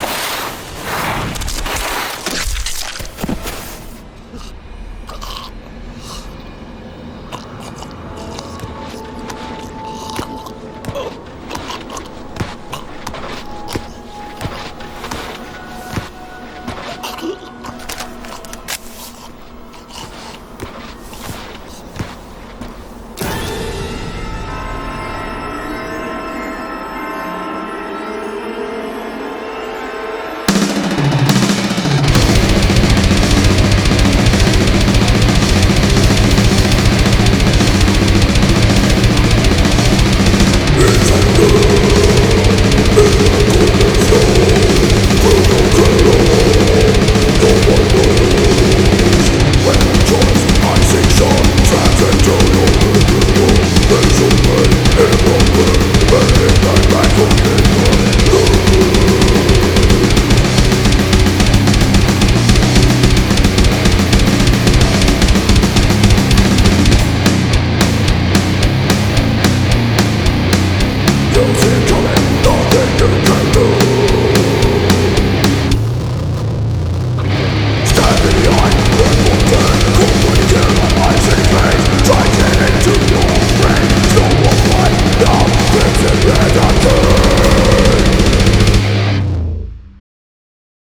pure brutal death sounds suitable for christmas